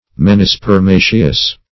Search Result for " menispermaceous" : The Collaborative International Dictionary of English v.0.48: Menispermaceous \Men`i*sper*ma"ceous\, a. [Gr. mh`nh the moon + spe`rma seed.]